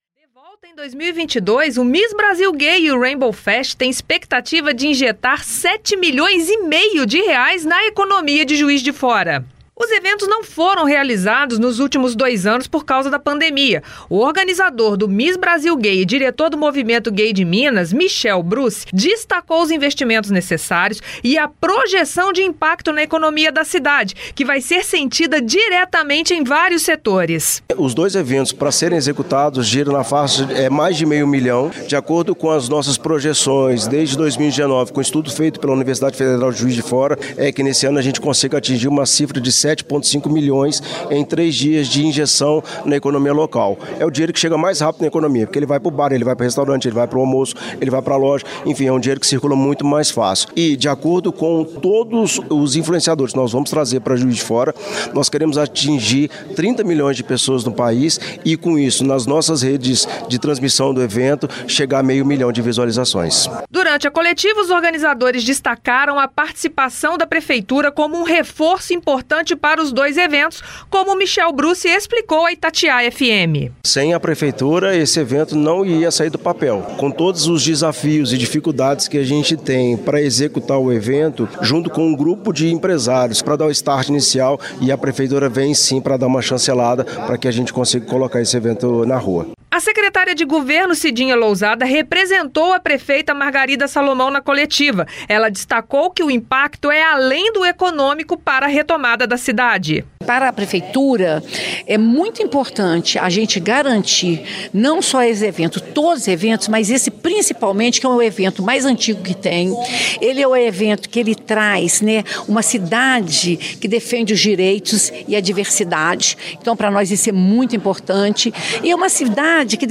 jf-juiz-de-fora-Coletiva-Miss-Brasil-Gay-e-Rainbow-Fest-2022.mp3